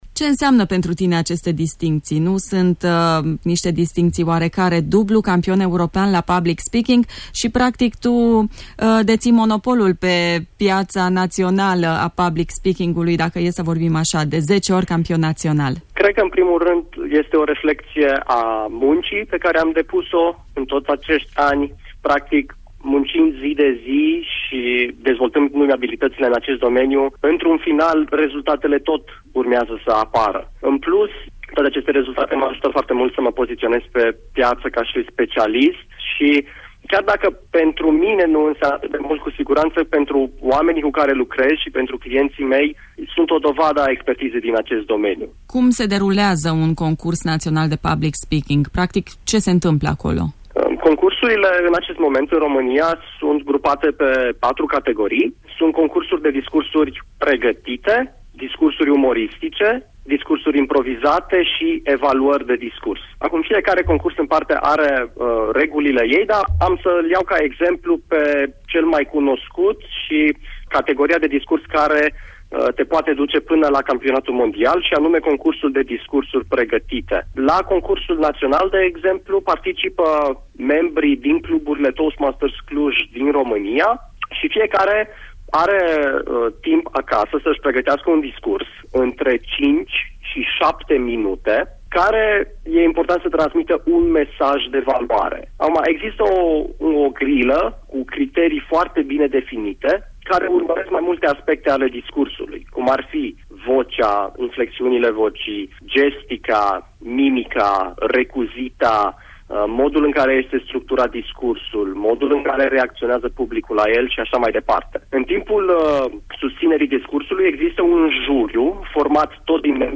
interviu-roro.mp3